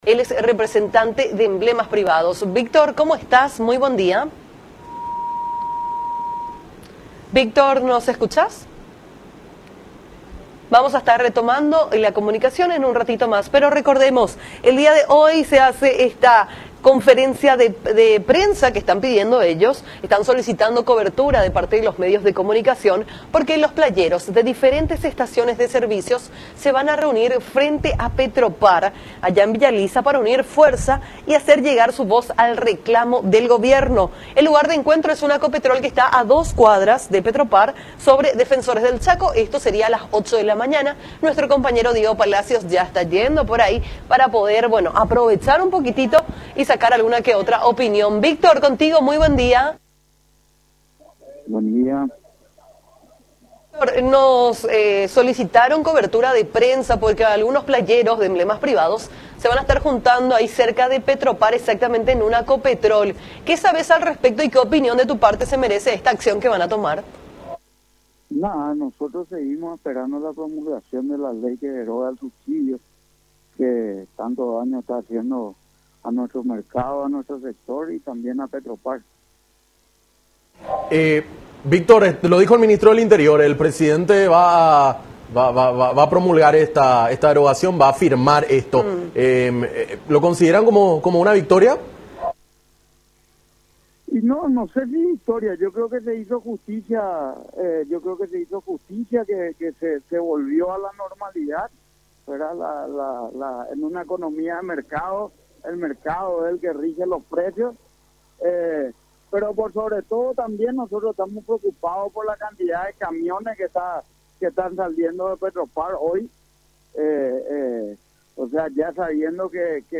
en diálogo con Nuestra Mañana por Unión TV.